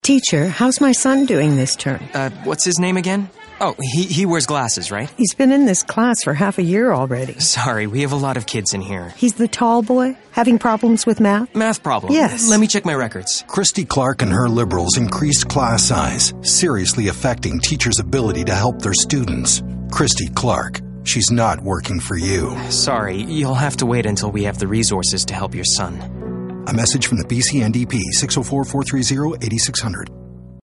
Radio ad 2 - Who is your son